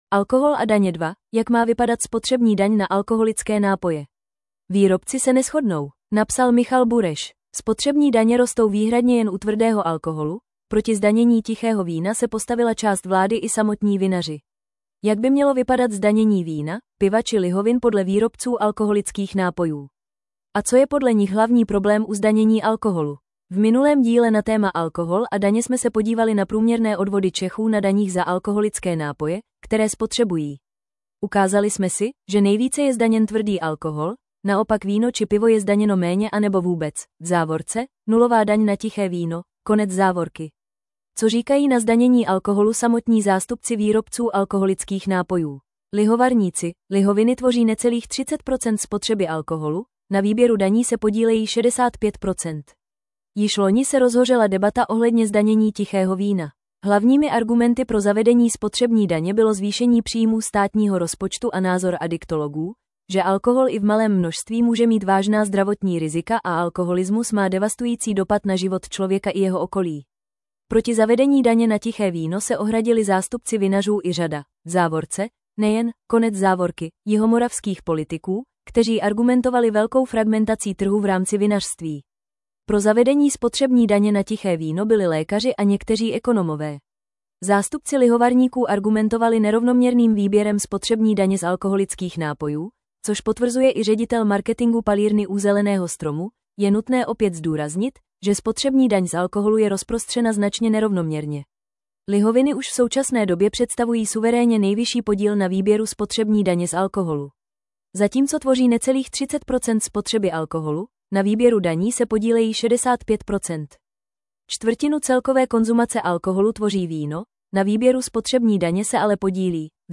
Rychlost přehrávání 0,5 0,75 normální 1,25 1,5 Poslechněte si článek v audio verzi 00:00 / 00:00 Tento článek pro vás načetl robotický hlas.